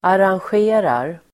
Uttal: [aransj'e:rar]